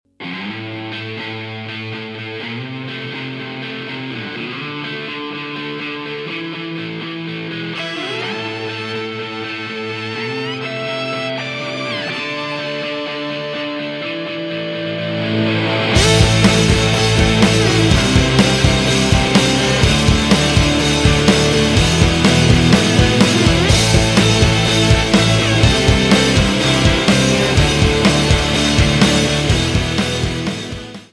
рок , инструментальные , без слов
фолк-рок